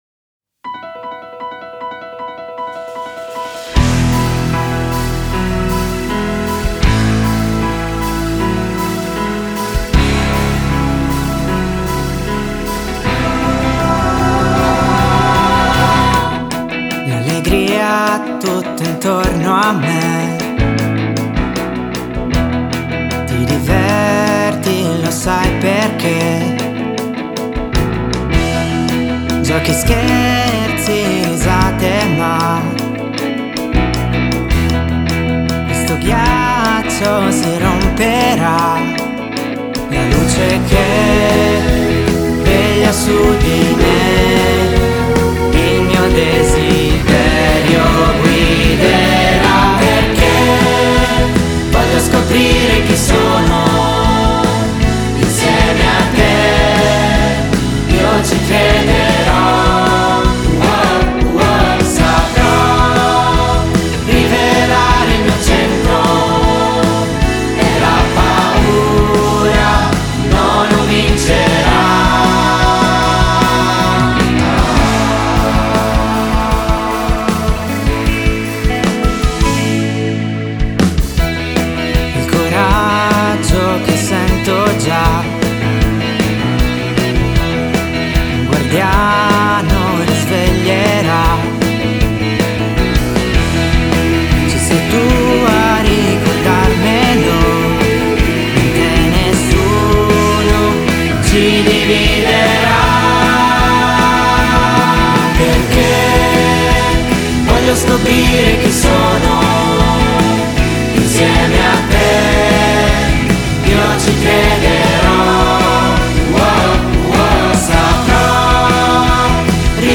Inno completo